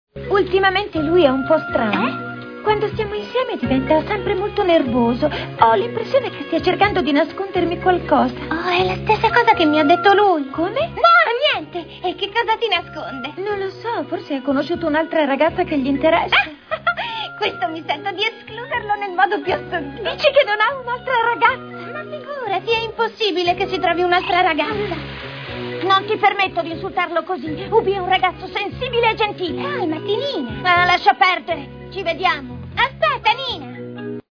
nel cartone animato "Sailor Moon e il cristallo del cuore", in cui doppia Nina.